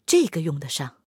SU-122A获得资源语音.OGG